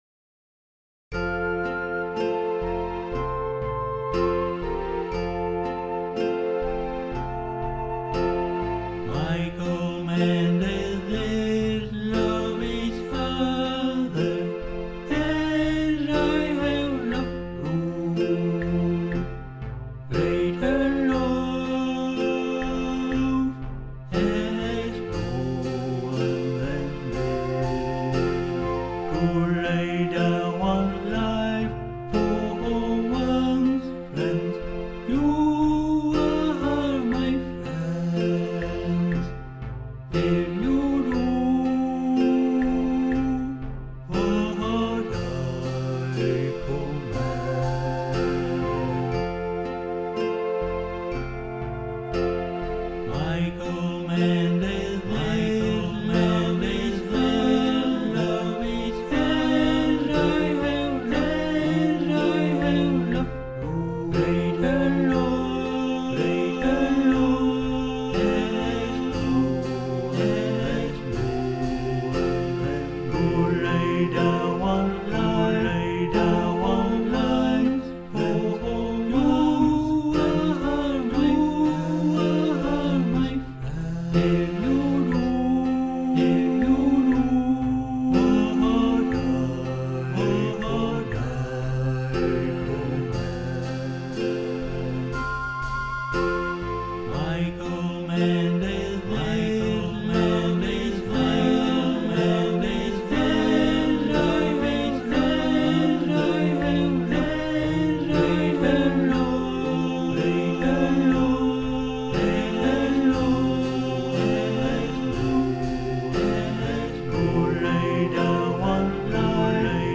[Karaoke Video with vocal]
A three part round